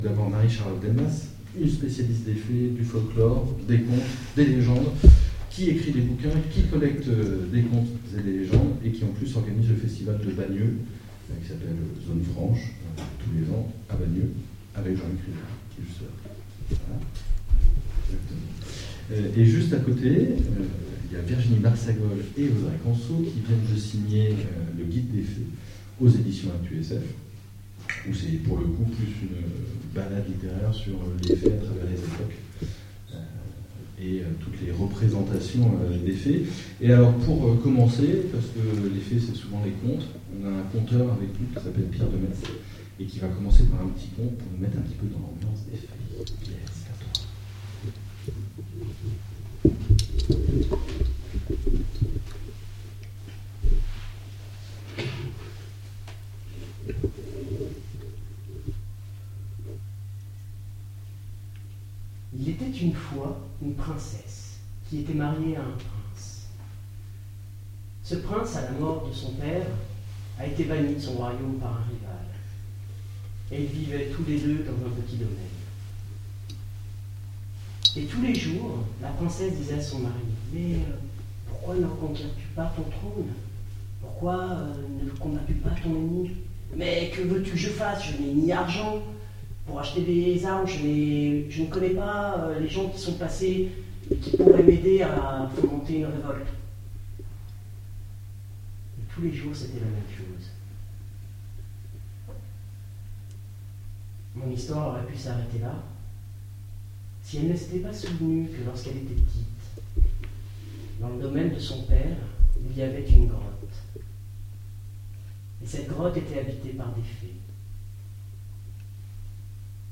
Conférence sur les Fées